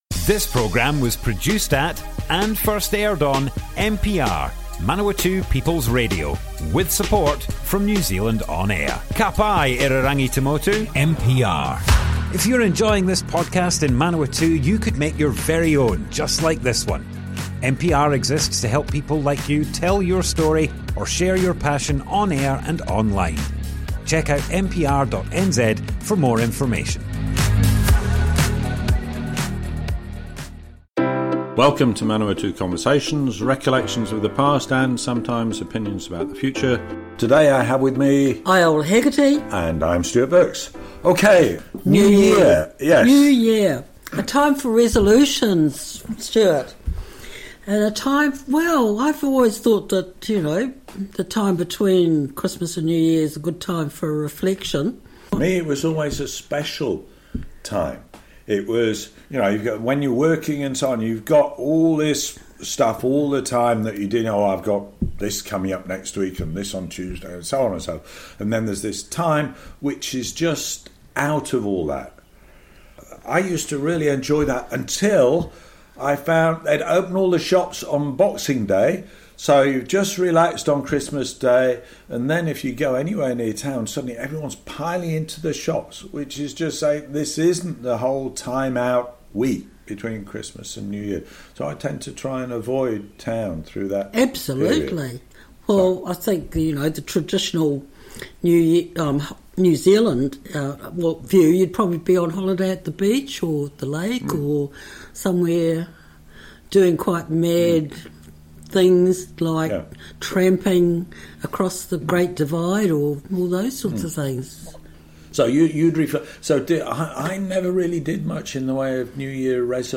Manawatu Conversations Object type Audio More Info → Description Broadcast on Manawatu People's Radio 2nd January 2024.
oral history